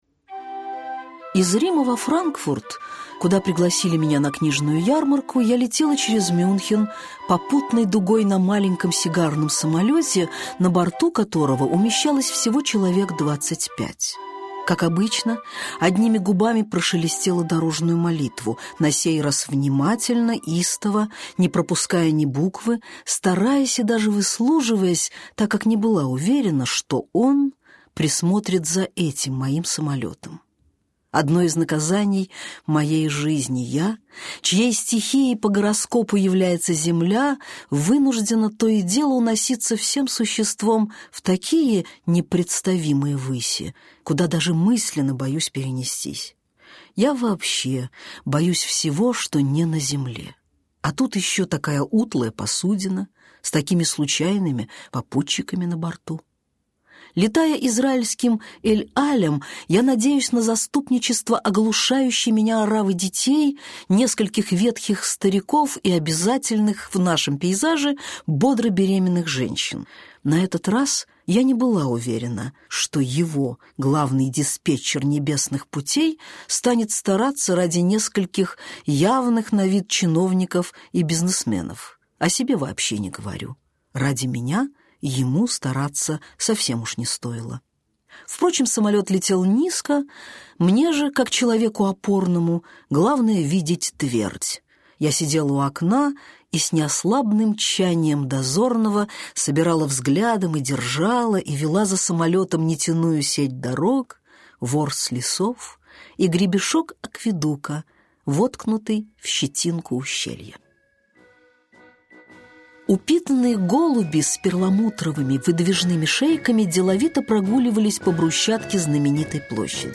Аудиокнига Коксинель | Библиотека аудиокниг
Aудиокнига Коксинель Автор Дина Рубина Читает аудиокнигу Дина Рубина.